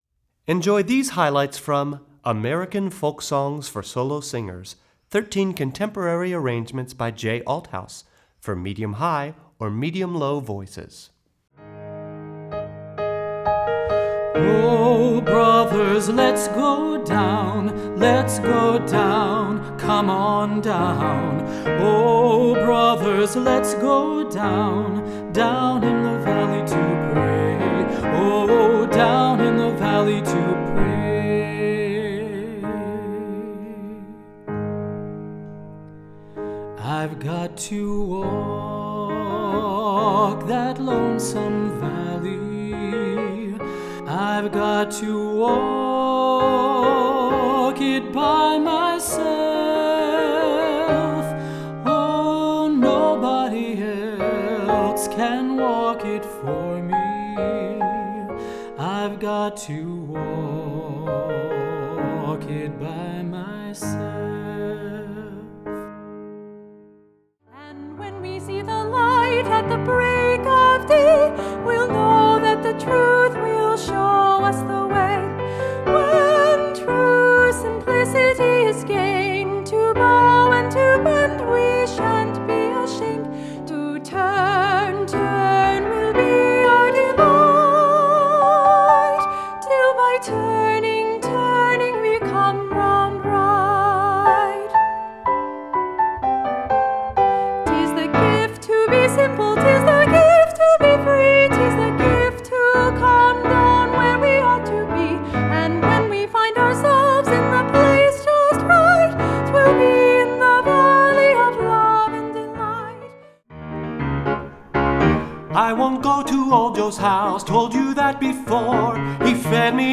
Voicing: Medium-High Voice